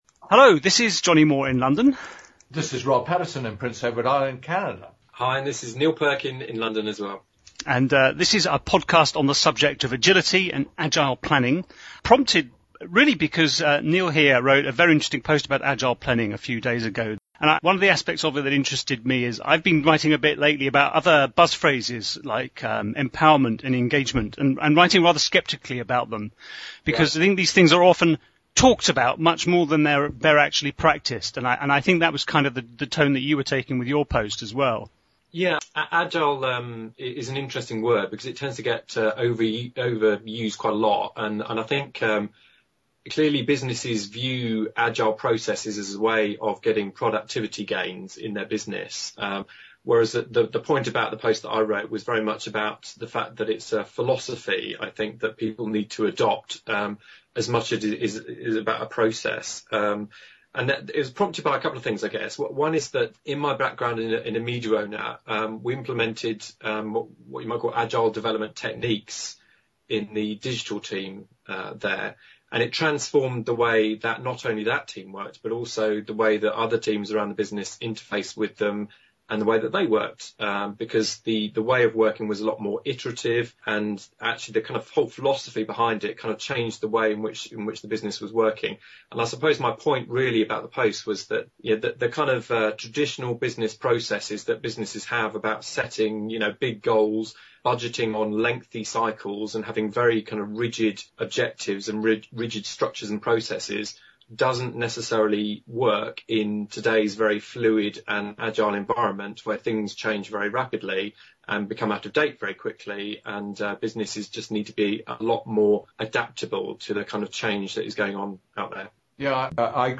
It’s the usual non-linear kind of conversation exploring what makes for agility in organisations and what gets in the way. We wander off into wider topics of education and innovation along the way.